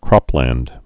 (krŏplănd)